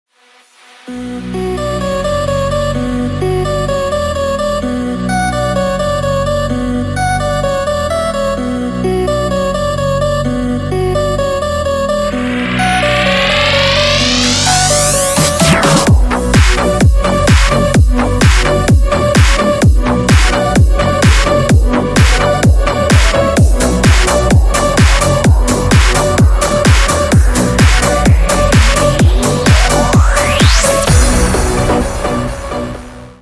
ритмичные
dance
без слов
club
качающие
Bass
electro house
Dance House
Крутой мелодичный качающий рингтон